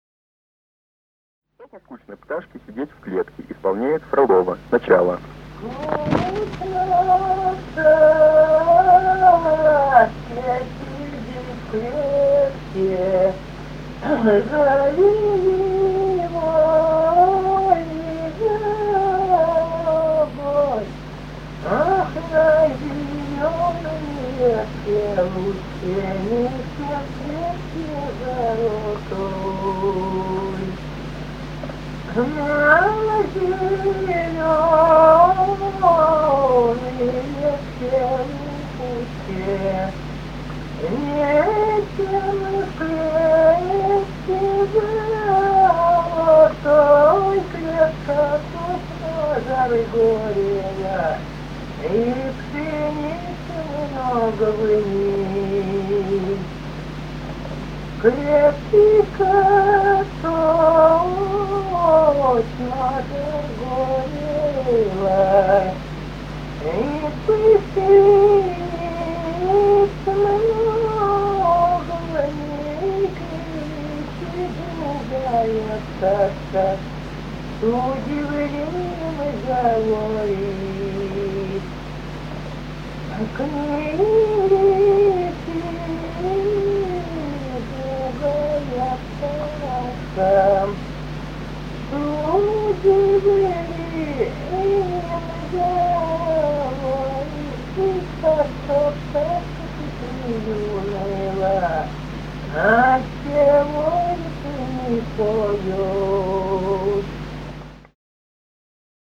Русские народные песни Владимирской области 32. Скучно пташке сидеть в клетке (лирическая) с. Михали Суздальского района Владимирской области.